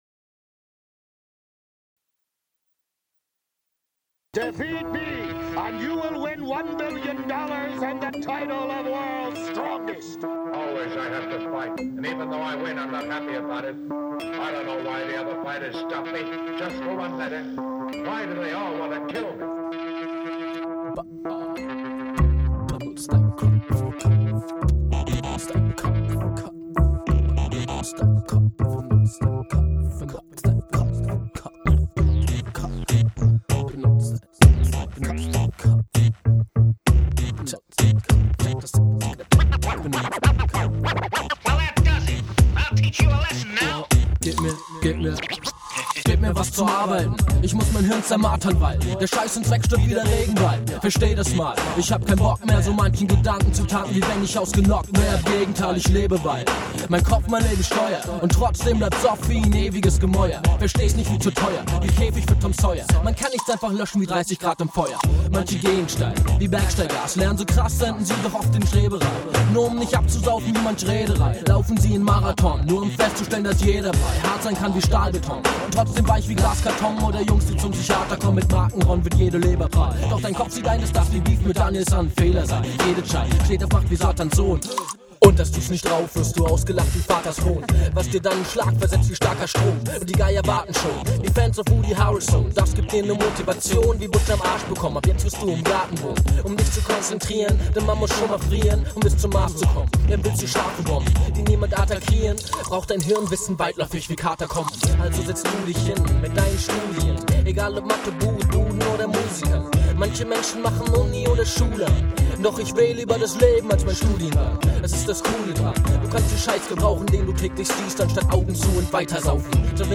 HipHop project